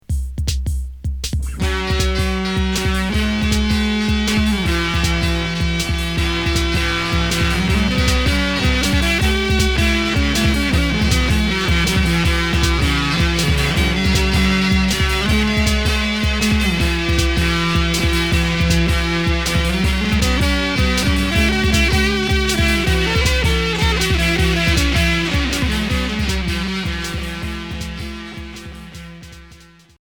Psyché rock